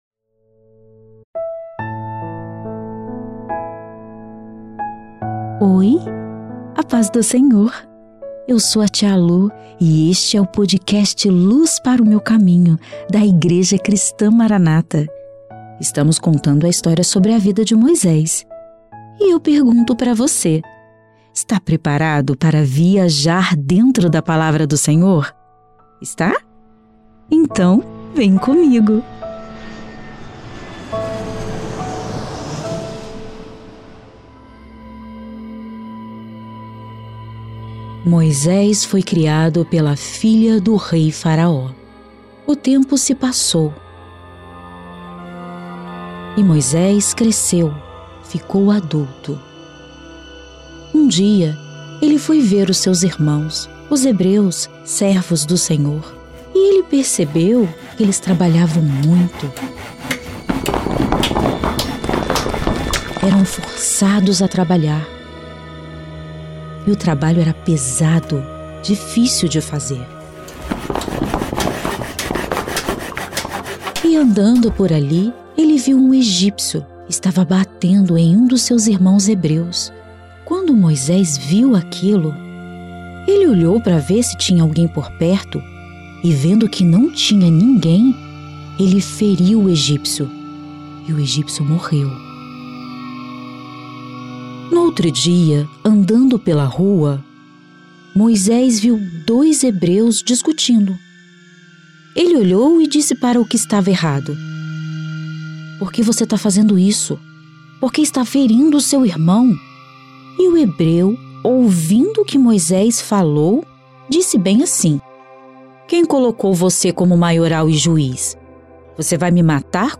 O quadro Luz Para o Meu Caminho traz histórias da bíblia narradas em uma linguagem para o público infantil.